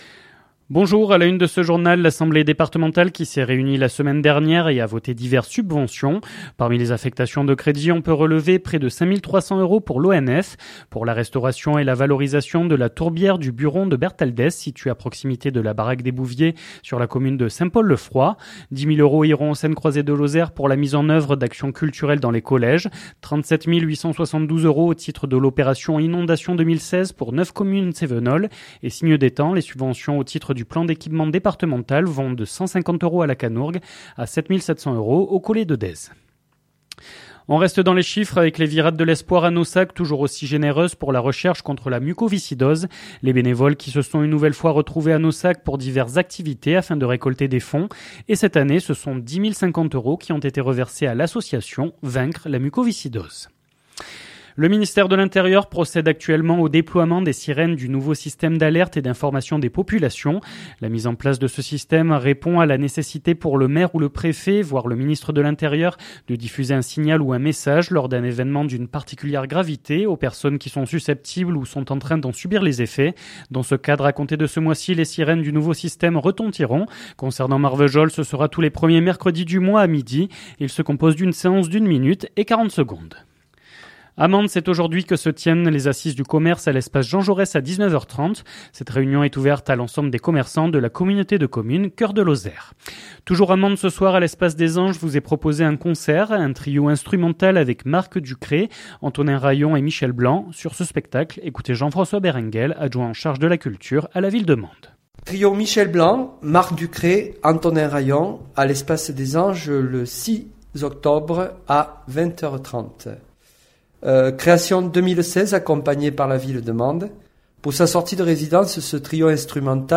Les informations locales
• Un trio instrumental à l’espace des anges, entretien avec Jean-François Berenguel adjoint en charge de la culture